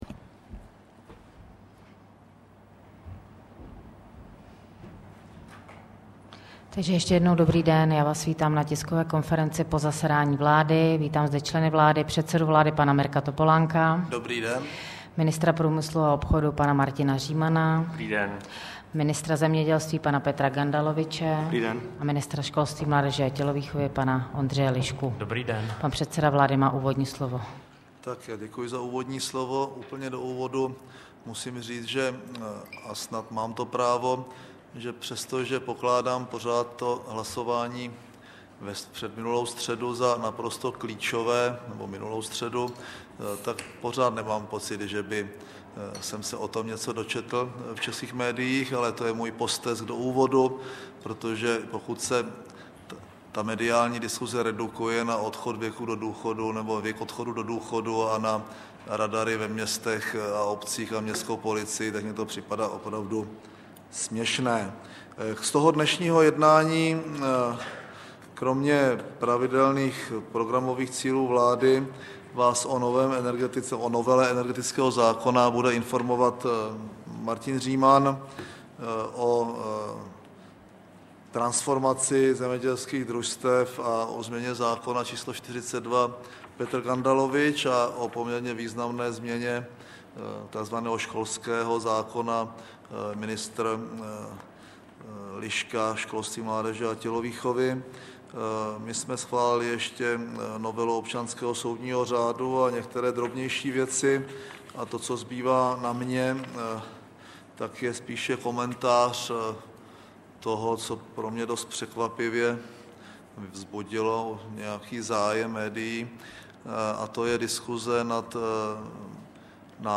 Tisková konference po jednání vlády ČR 2. července 2008